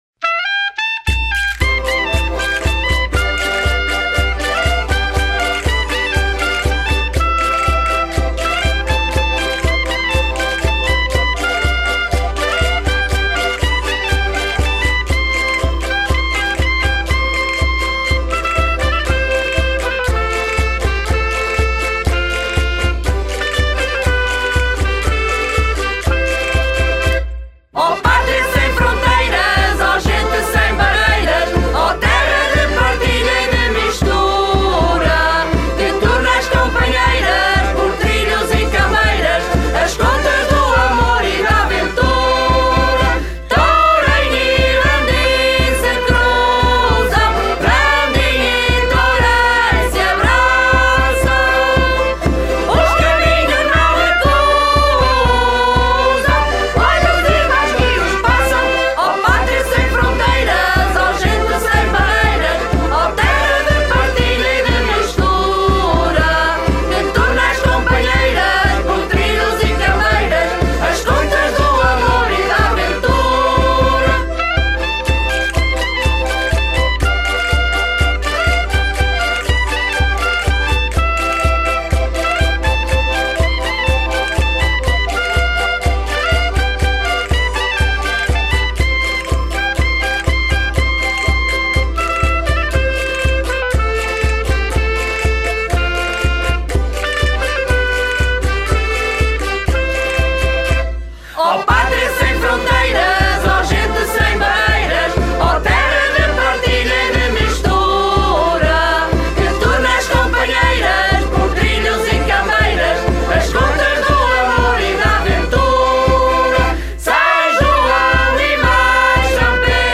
A animação não foi esquecida e a festa ficou pautada por sons tradicionais. HINO - Encontro transfronteiriço Tourém-Randin